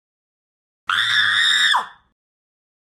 Звуки визга
Женщина протестует визгом